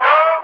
BWB X SUPA 2 - 36 VOX.wav